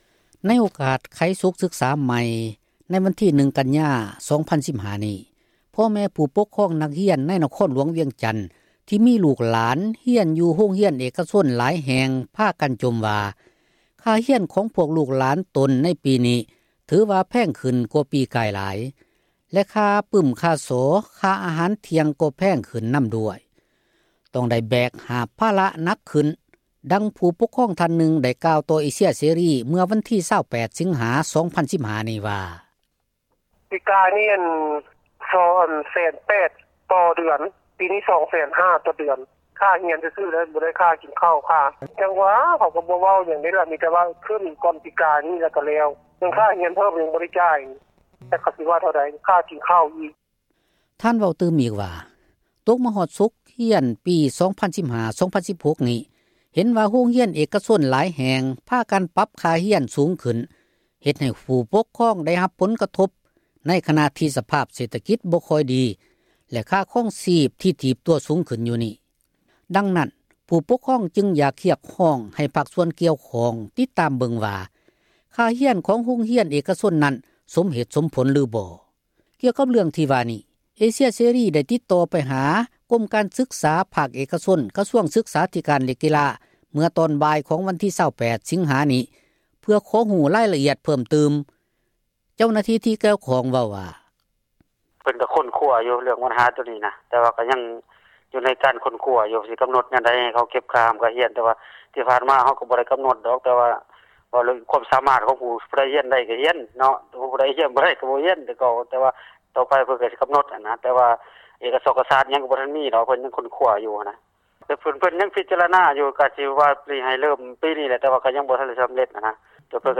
ໃນ ສົກປີ ການສຶກສາ ໃໝ່, ວັນທີ 1 ກັນຍາ 2015 ນີ້ ພໍ່ແມ່ ຜູ້ ປົກຄອງ ນັກຮຽນ ໃນ ນະຄອນຫຼວງ ວຽງຈັນ, ທີ່ ສົ່ງ ລູກຫຼານ ເຂົ້າ ຮຽນ ຢູ່ ໂຮງຮຽນ ເອກກະຊົນ ພາກັນ ຈົ່ມວ່າ, ຄ່າຮຽນ ໃນປີນີ້ ແພງຂຶ້ນ ກວ່າປີ ກາຍ ຫຼາຍ, ແລະ ຄ່າປື້ມ ຄ່າສໍ ຄ່າອາຫານ ທ່ຽງ ກໍ ແພງຂຶ້ນ ເຊັ່ນກັນ. ດັ່ງ ຜູ້ປົກຄອງ ທ່ານນຶ່ງ ໄດ້ ກ່າວຕໍ່ ເອເຊັຽ ເສຣີ ໃນວັນທີ 28 ສິງຫາ 2015 ນີ້ວ່າ: